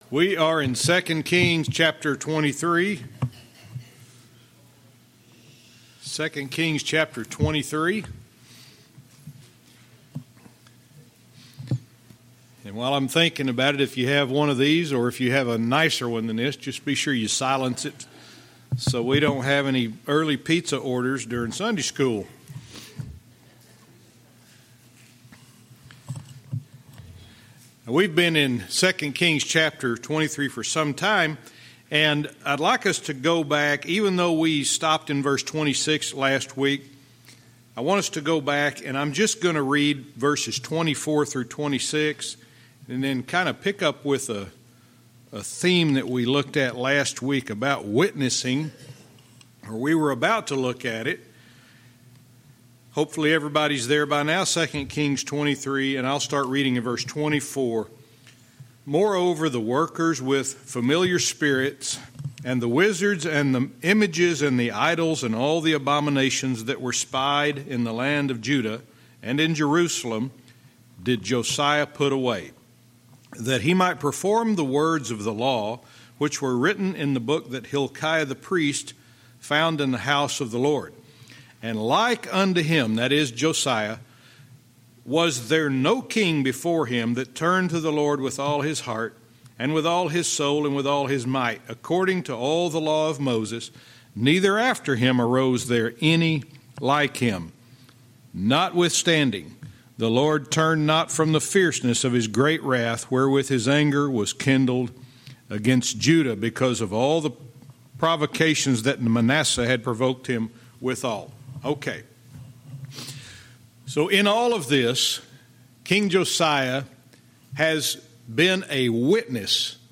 Verse by verse teaching - 2 Kings 23:27-29